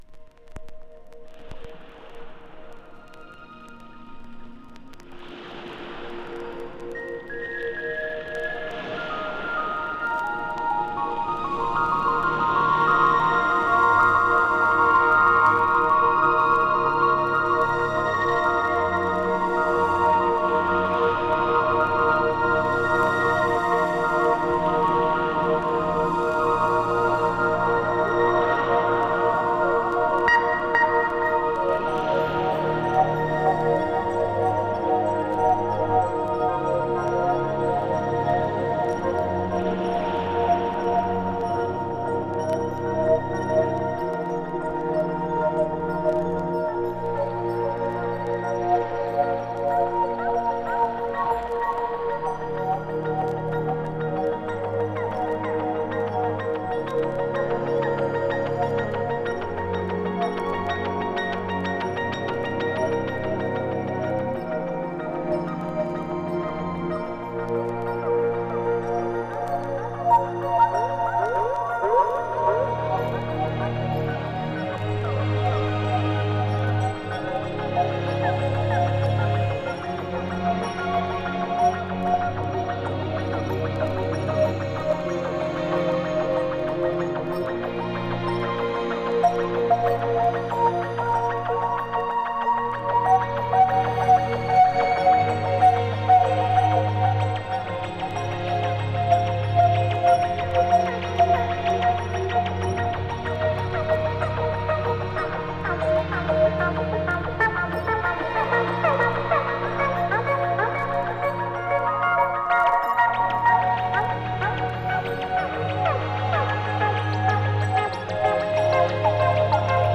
歌い上げる女性ヴォーカルと深遠なストリングスが印象的なトラックです。